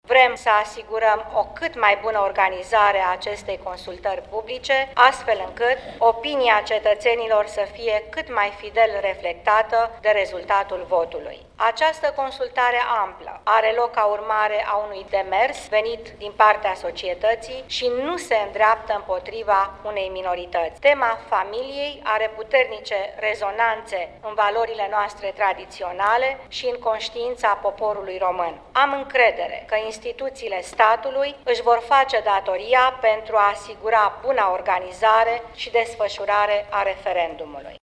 Executivul a luat toate măsurile necesare pentru asigurarea, în bune condiţii, a referendumului, a spus şi premierul Viorica Dăncilă la începutul şedinţei de guvern de astăzi. Viorica Dăncilă a precizat ca autorităţile au respectat calendarul propus în ceea ce priveste pregătirea tehnică: